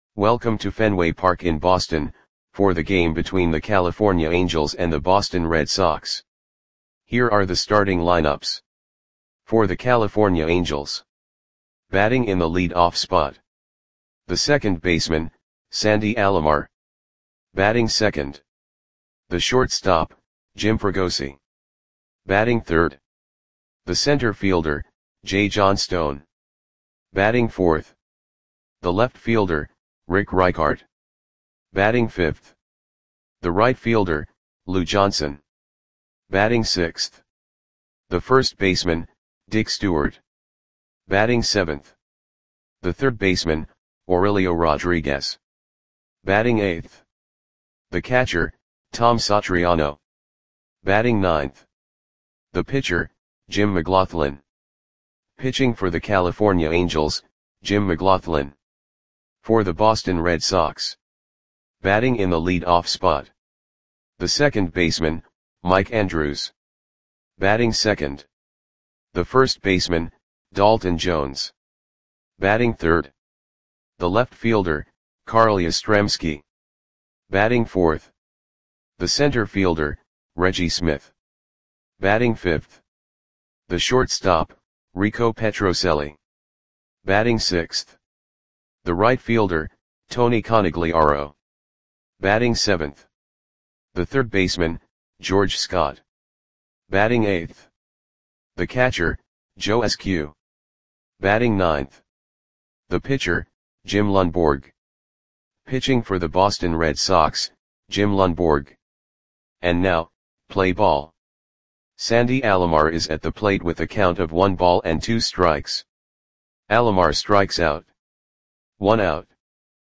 Lineups for the Boston Red Sox versus California Angels baseball game on May 21, 1969 at Fenway Park (Boston, MA).
Click the button below to listen to the audio play-by-play.